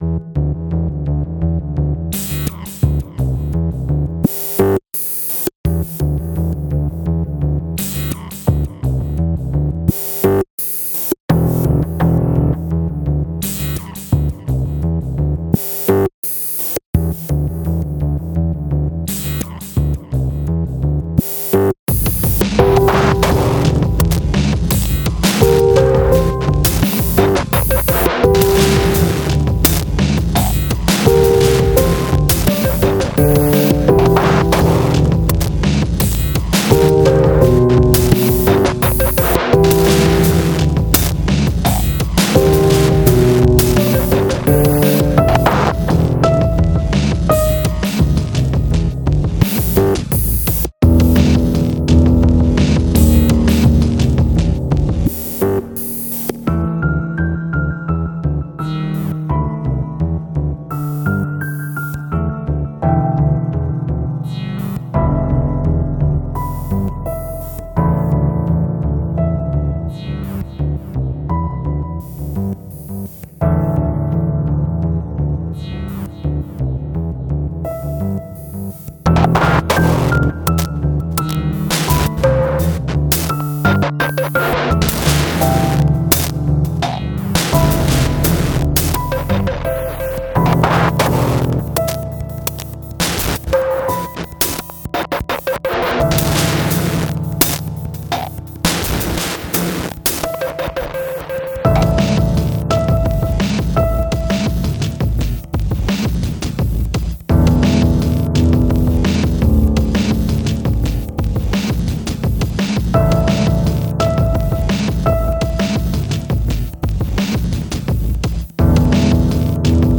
Industrial-Classical Fusion